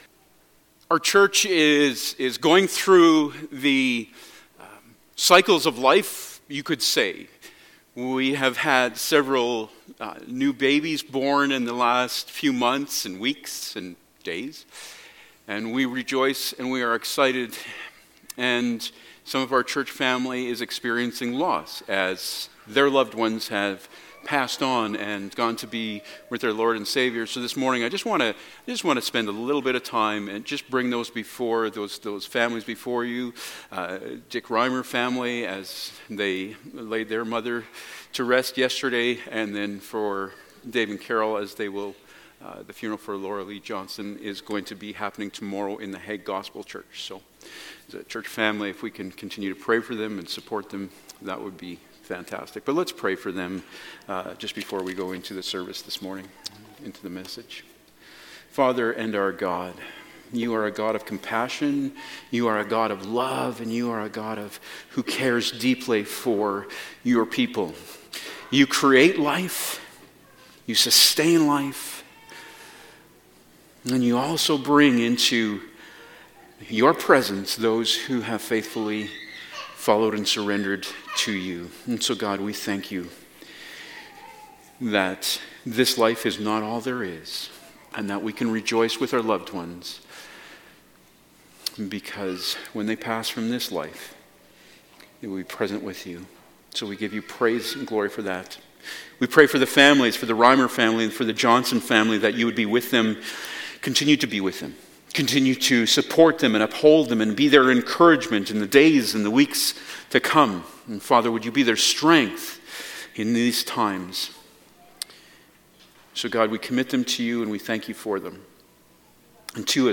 Amos 5 Service Type: Sunday Morning Topics: Repentance